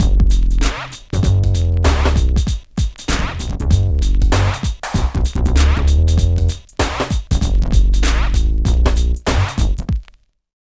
112 LOOP  -R.wav